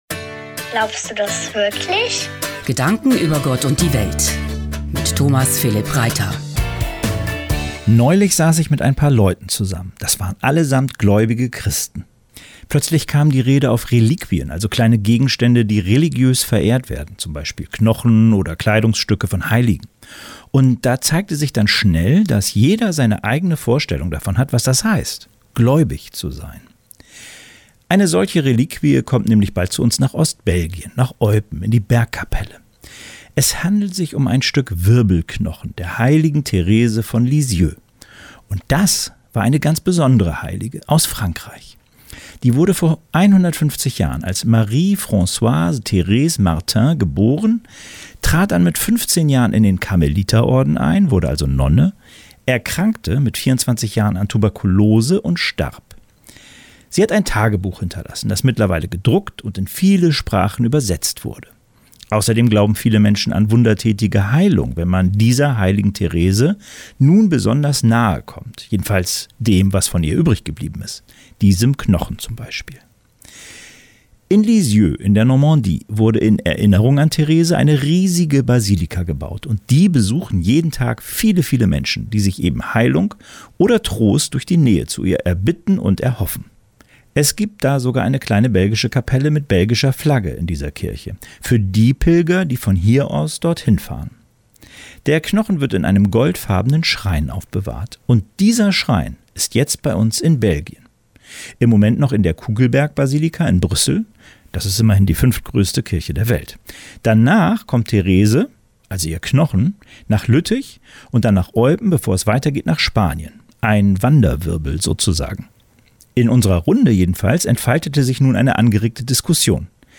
„Glaubst Du das wirklich?“, ein kleines Fenster zum Wochenende, läuft immer freitags bei „Wake up!“.